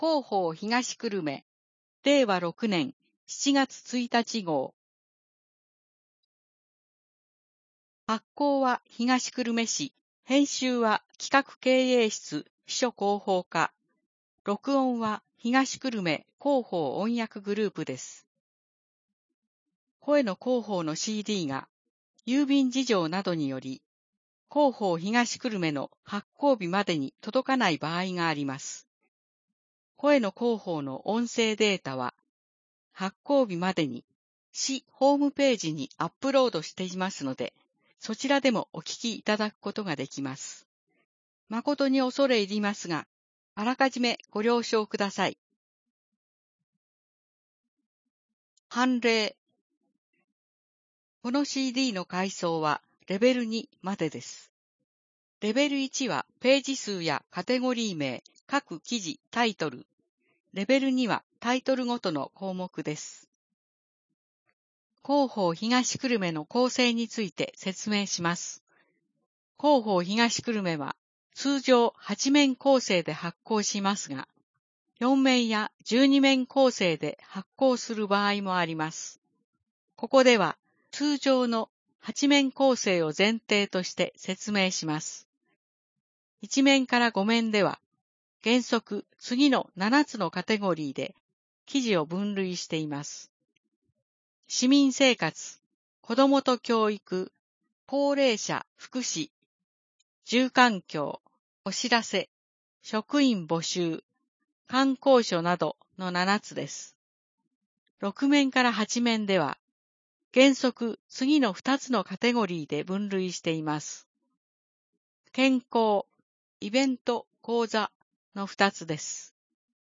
声の広報（令和6年7月1日号）